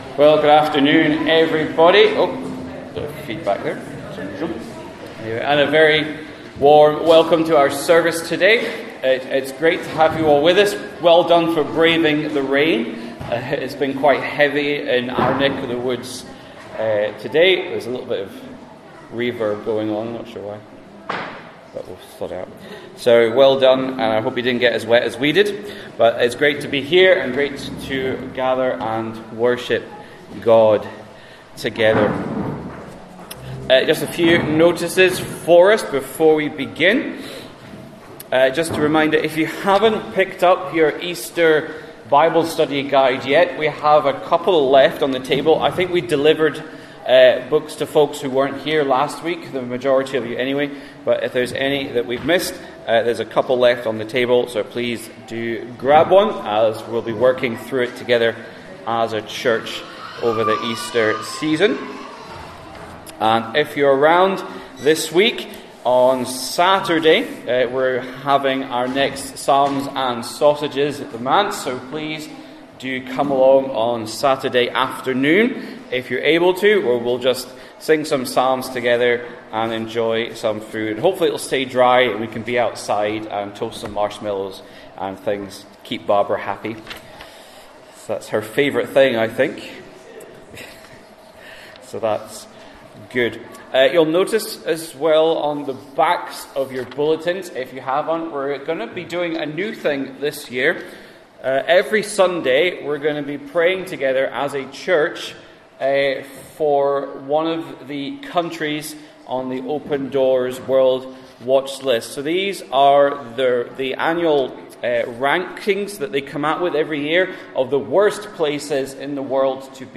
Matthew Passage: Matthew 6:16-24 Service Type: Inverinate AM Download Files Bulletin « Why do nations rage?